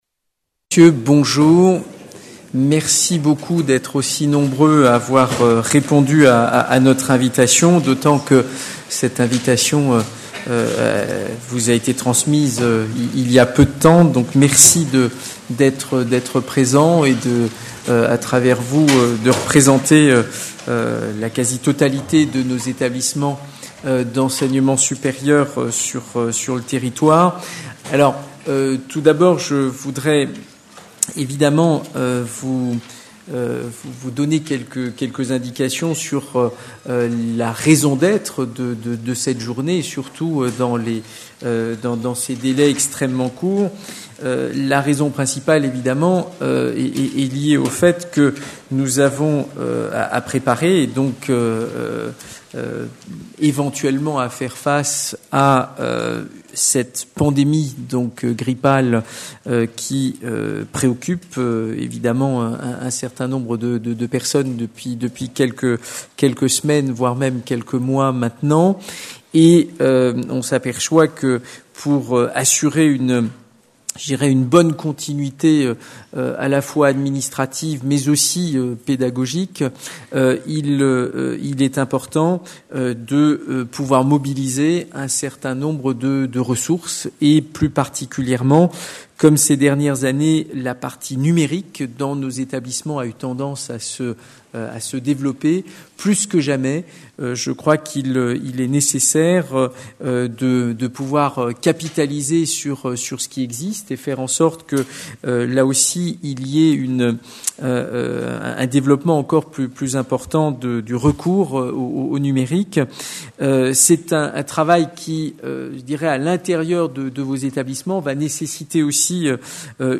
Séminaire pandémie grippale - 1 | Canal U
Séminaire de travail organisé par La DGESIP en collaboration avec la SDTICE dans le cadre du plan de continuité pédagogique en cas de pandémie grippale. Numérique et continuité pédagogique